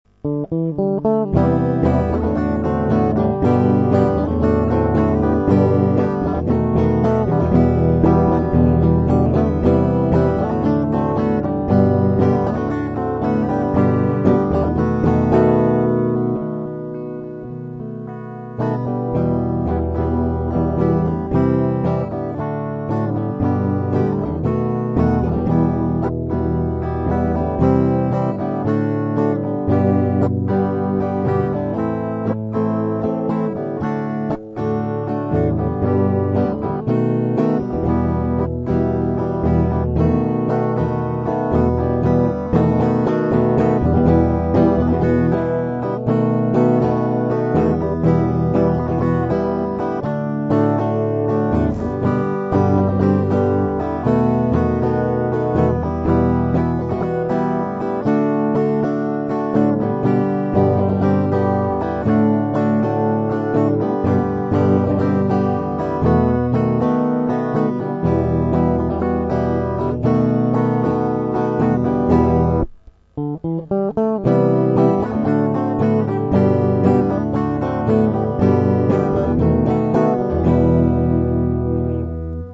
Проигрыш (Cmaj7 - D - G - D - G - H7 - Em):
mp3 - проигрыш, куплет (сокр), припев